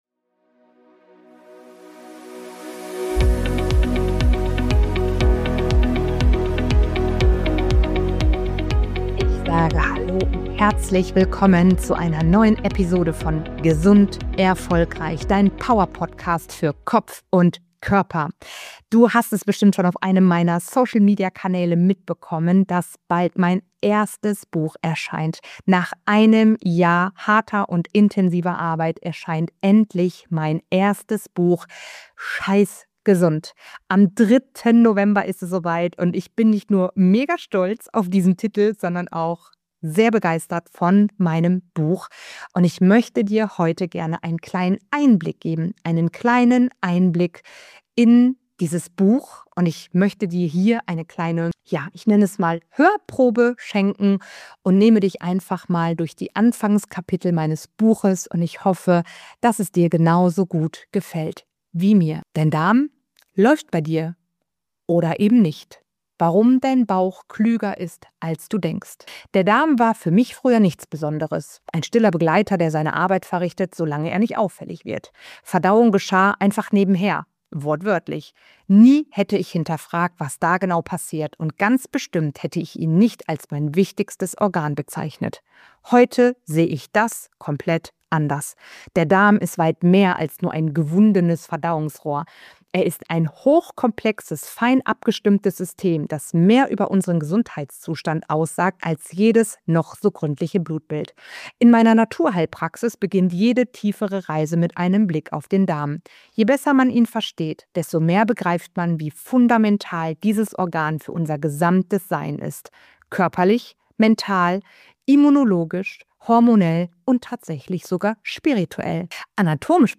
046 „Scheißgesund“ – Die Buchlesung! Exklusiver Einblick in mein erstes Buch ~ Gesund erfolgreich: Dein Powerpodcast für Kopf & Körper Podcast